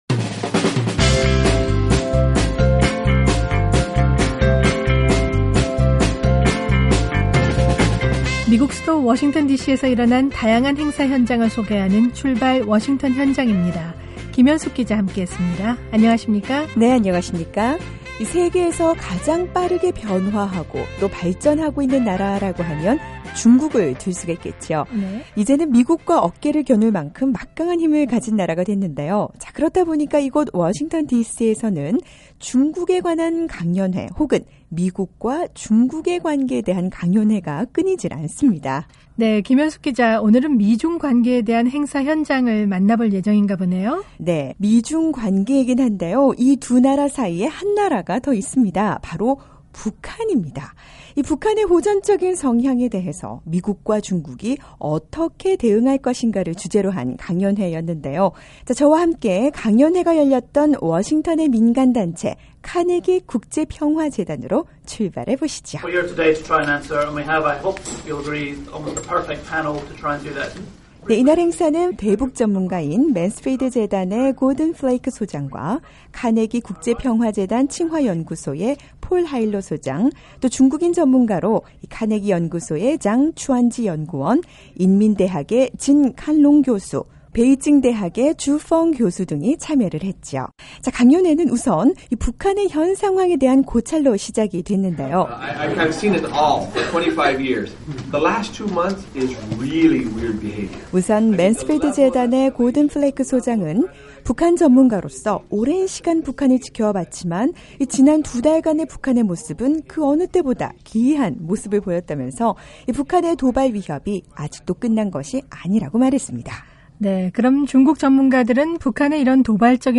미국의 동북아 전문가와 중국의 국제관계 전문가들이 모여 북한에 대한 중국의 시각 변화에 대해 의견을 나눴던 카네기 평화재단의 강연회 현장으로 출발해보시죠!